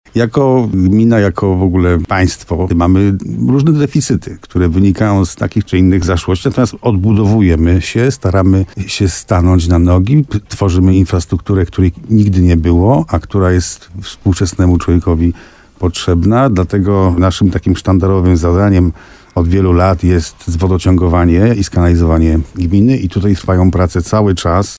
– Dużą część tych pieniędzy zaplanowaliśmy na wydatki związane z kanalizacją i wodociągami – mówił w programie Słowo za Słowo w RDN Nowy Sącz, Benedykt Węgrzyn, wójt gminy Dobra.